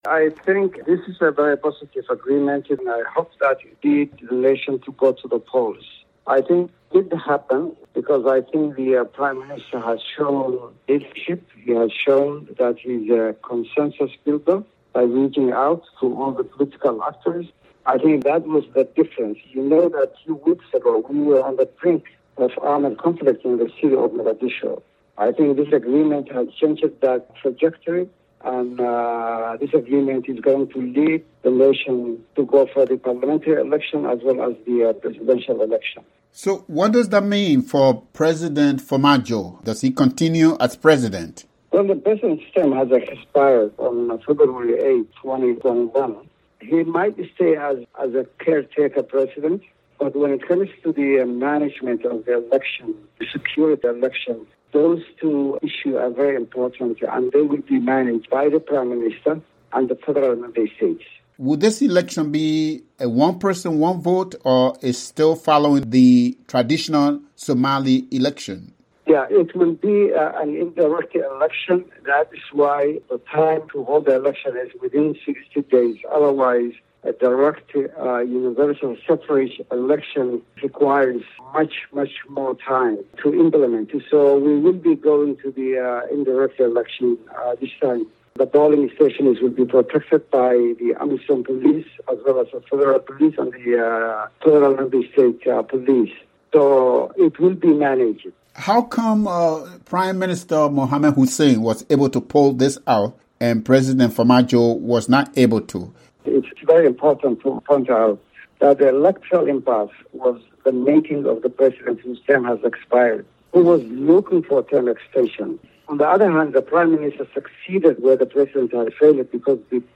spoke to Mohamed who said polling stations would be protected by AMISOM police given unstable Somali security.